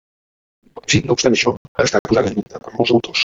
Pronunciato come (IPA) [puˈza.ðə]